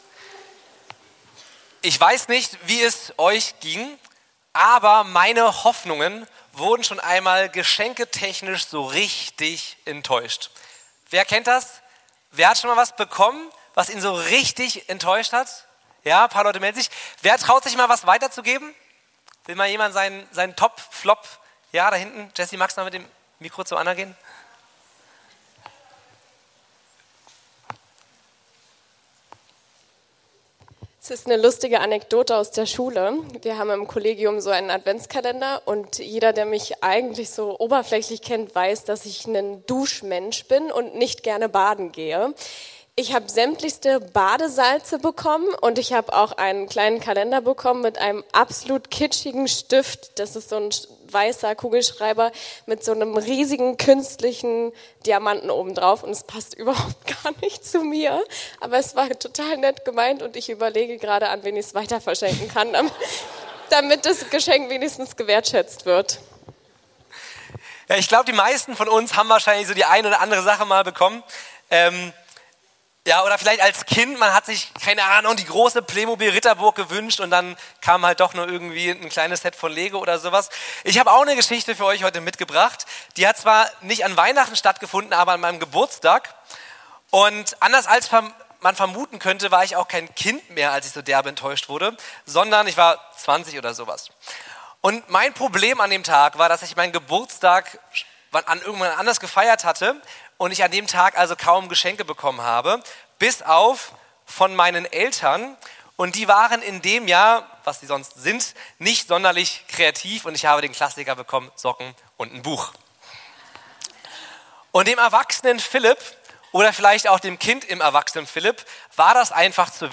Beschreibung vor 4 Monaten Diese Predigt befasst sich mit der Spannung zwischen unseren subjektiven Hoffnungen auf Sicherheit, Gesundheit oder Erfolg und der oft ernüchternden Realität. Anhand der Geschichte von Josef und Maria wird aufgezeigt, wie Gott menschliche Pläne durchkreuzt, um Raum für seine größere Geschichte zu schaffen.